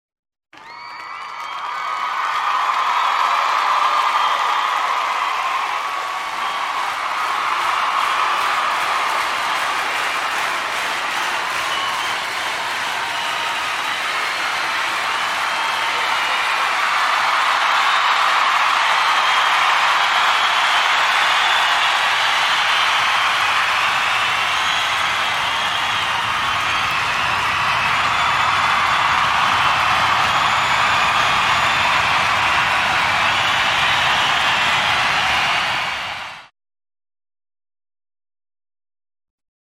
SFX – CHEERING – CONCERT
SFX-CHEERING-CONCERT.mp3